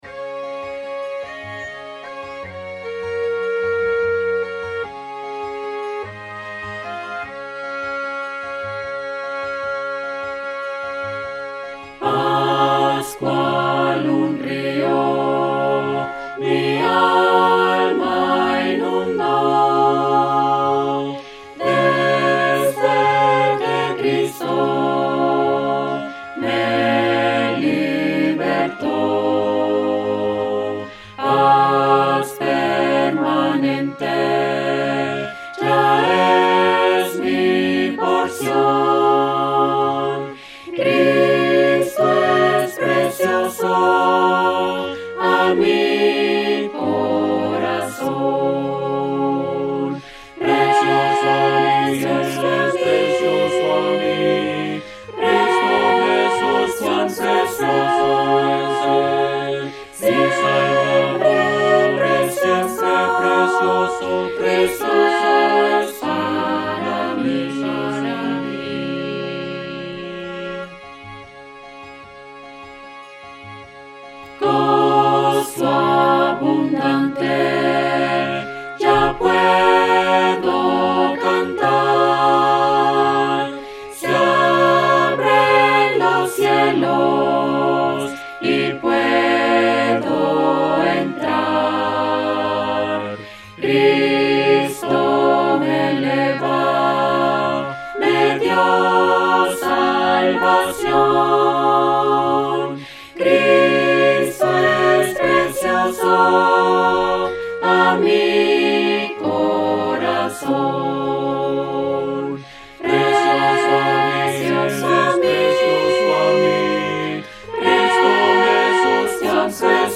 Cantado (Descargar audio)